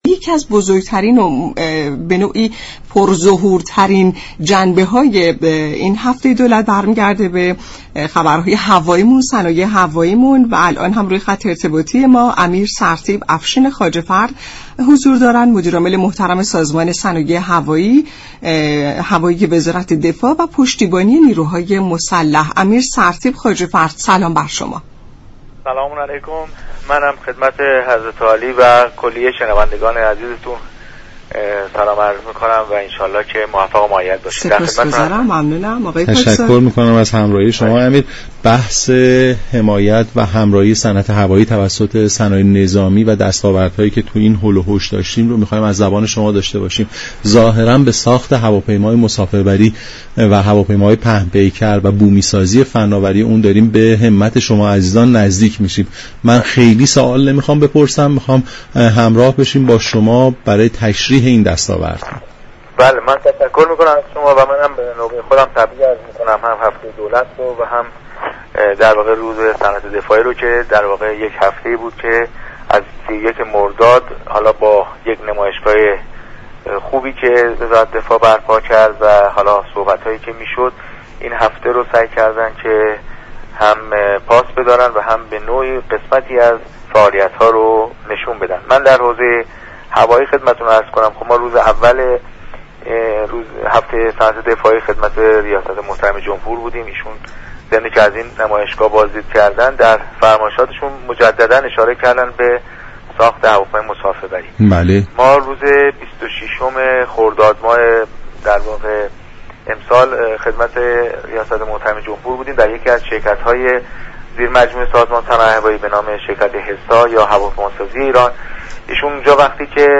به گزارش شبكه رادیویی ایران، سردار سرتیپ افشین خواجه فرد مدیر عامل سازمان صنایع هوایی وزارت دفاع و پشتیبانی نیروهای مسلح در برنامه «نمودار» به دستاوردهای صنعت هوایی كشور و ساخت هواپیماهای مسافربری پرداخت و گفت: سازمان هوایی كشور ساخت هواپیمای مسافربری را از سال های خیلی دوره آغاز كرده است با شدت یافتن تحریم های علیه ایران، سازمان مبنای كار خود را بیشتر در حمایت از ناوگان تجاری را در اولویت كار خود قرار داد.